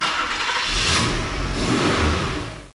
Play, download and share Lamborghinicars original sound button!!!!